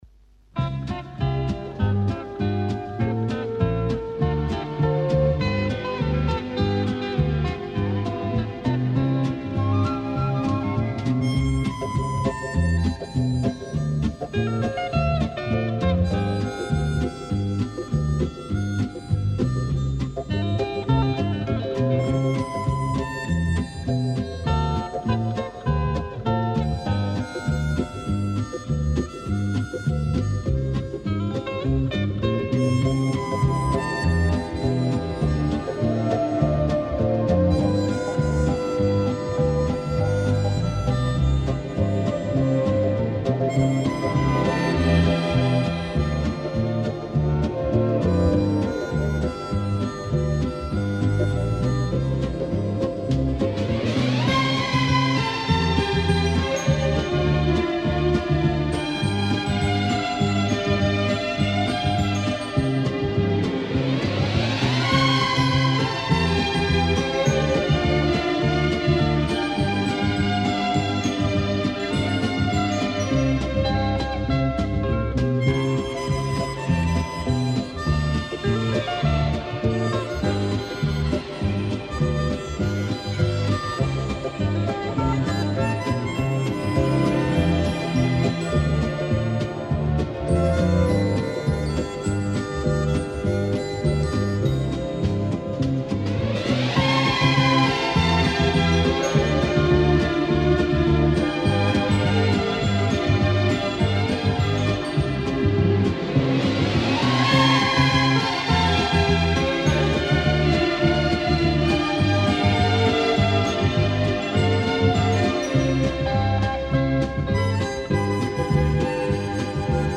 Genre:Jazz, Pop
Style:Easy Listening